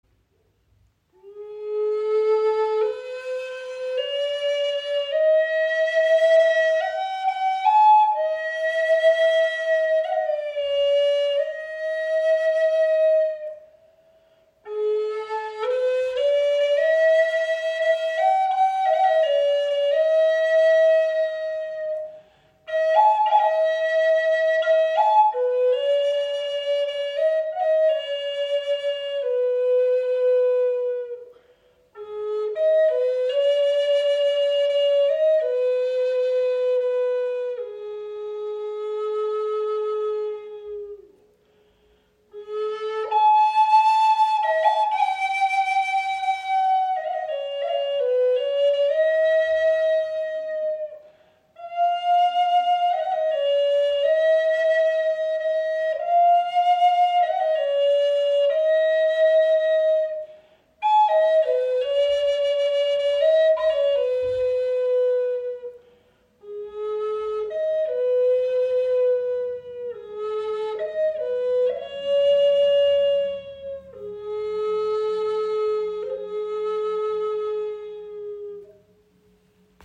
Deine EarthTone 432 Hz Flöte aus edlem Holz verbindet klare, warme Töne mit heilender Schwingung.
Diese EarthTone 432 Hz Flöte ist in A-Moll gestimmt, und alle anderen Töne sind auf diese heilende Frequenz ausgerichtet.
High Spirits Flöten sind Native American Style Flutes.
EarthTone 432 Hz 'A' Walnut Wood Flute - Sound Sample